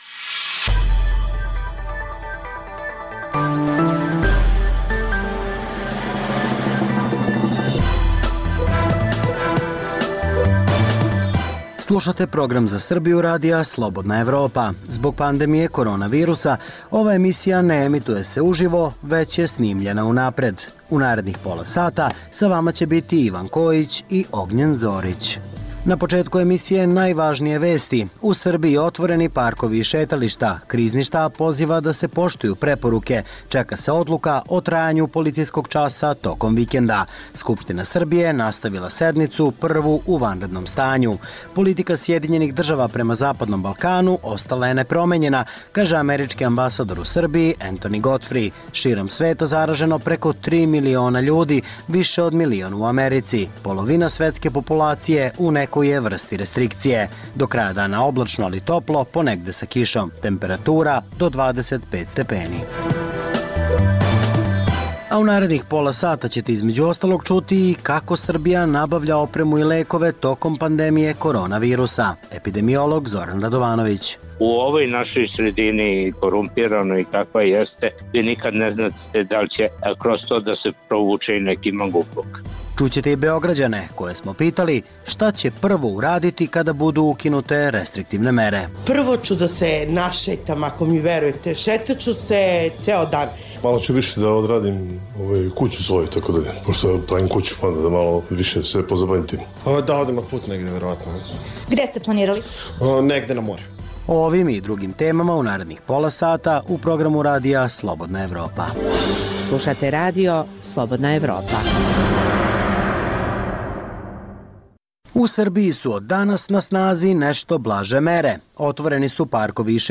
Kako Srbija nabavlja opremu i lekove tokom pandemije korona virusa? Čućete i Beograđane koje smo pitali šta će prvo uraditi kada budu ukinute mere? U Srbiji su od danas na snazi nešto blaže mere - otvoreni su parkovi i šetališta.